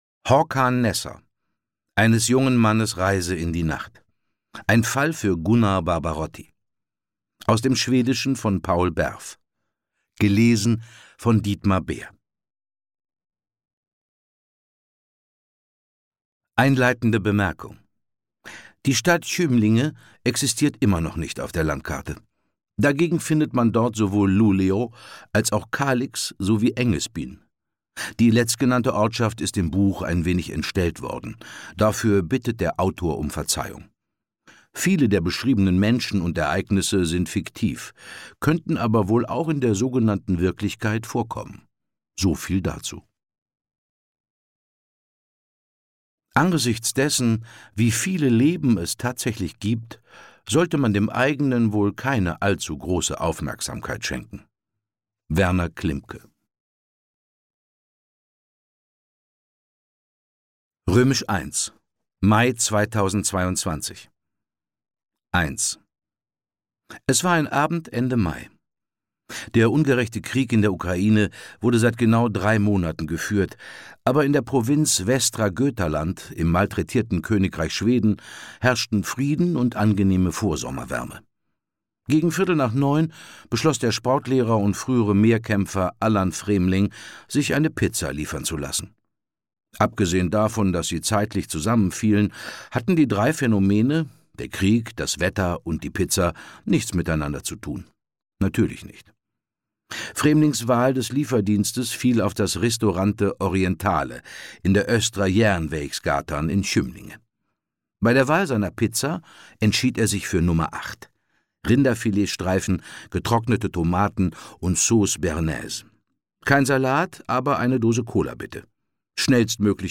Ungekürzte Lesung mit Dietmar Bär (1 mp3-CD)
Dietmar Bär (Sprecher)